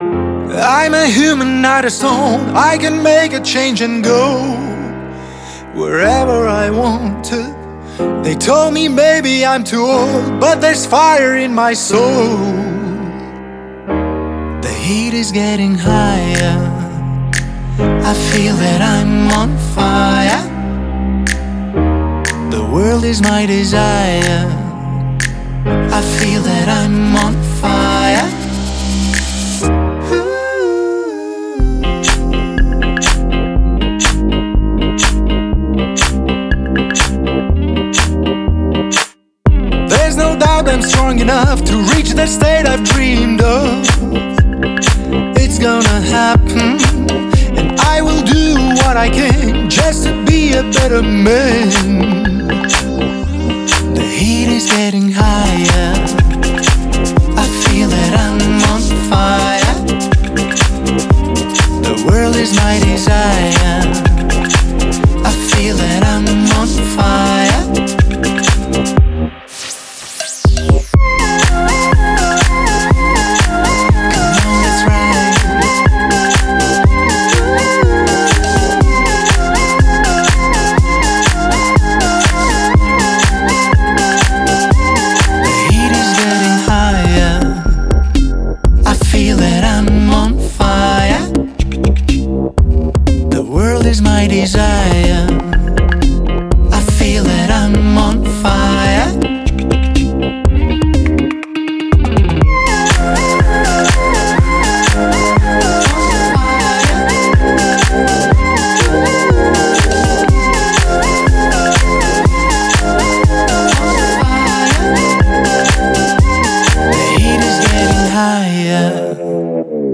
BPM122
MP3 QualityMusic Cut